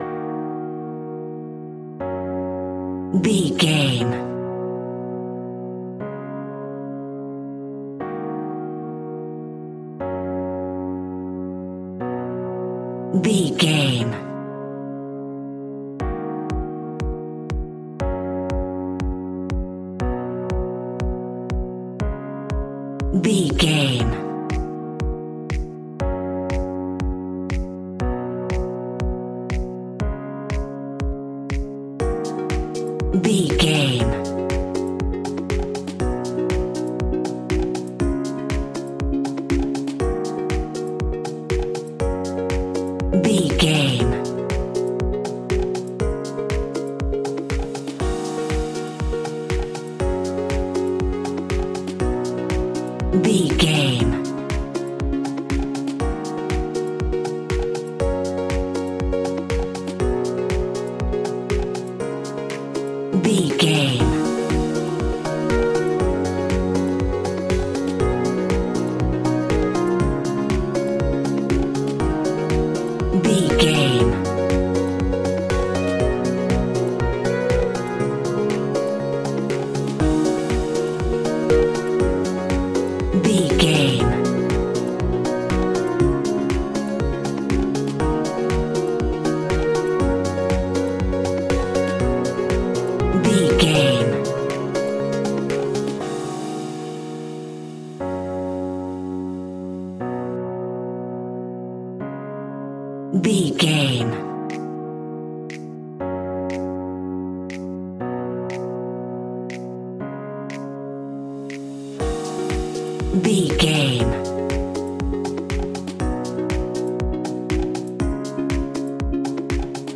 Aeolian/Minor
E♭
groovy
uplifting
hypnotic
dreamy
smooth
piano
drum machine
synthesiser
electro house
funky house
instrumentals
synth leads
synth bass